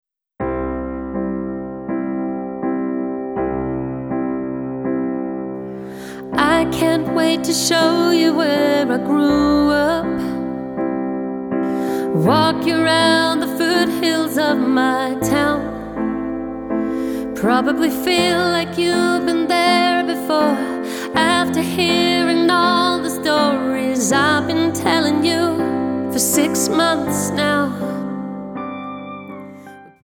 --> MP3 Demo abspielen...
Tonart:C Multifile (kein Sofortdownload.